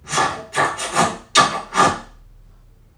NPC_Creatures_Vocalisations_Robothead [38].wav